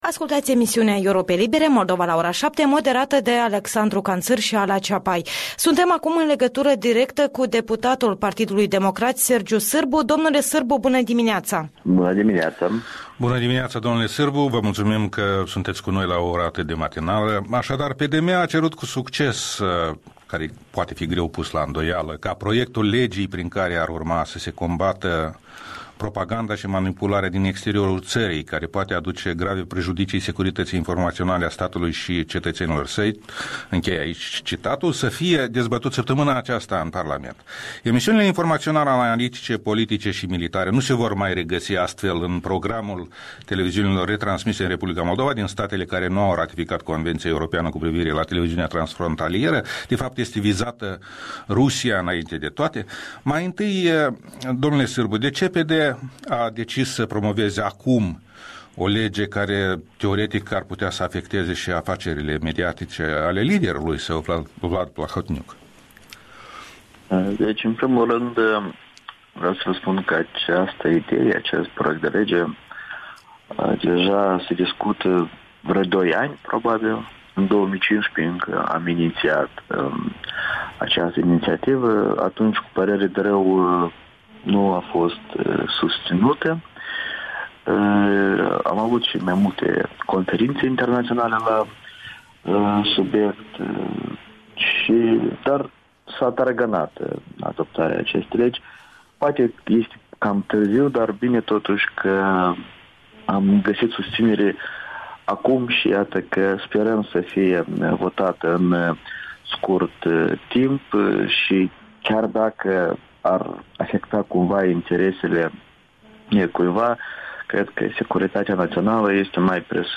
Interviul dimineții: Sergiu Sîrbu (PD)